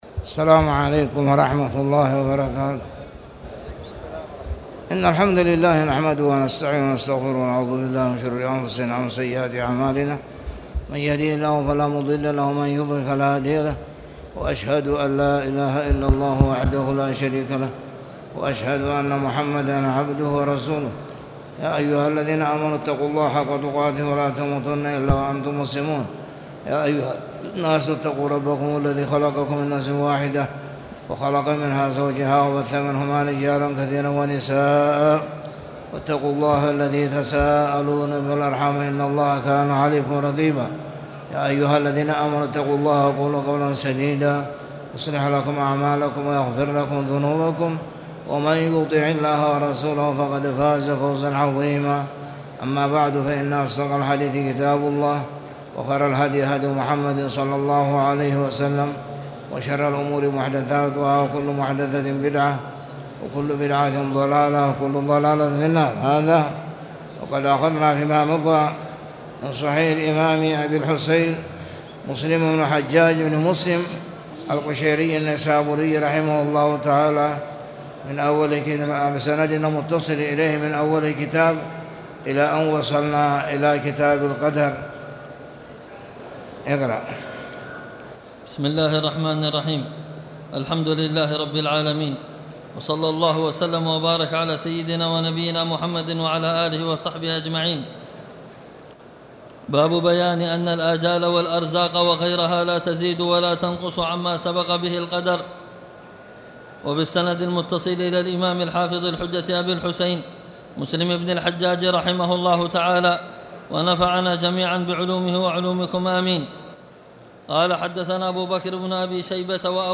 تاريخ النشر ٢٣ جمادى الأولى ١٤٣٨ هـ المكان: المسجد الحرام الشيخ